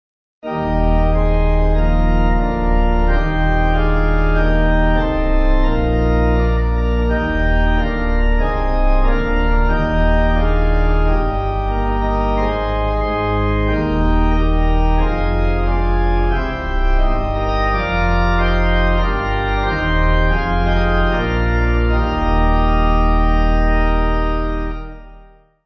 Organ
(CM)   5/Eb